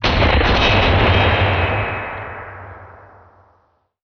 explosion_21.wav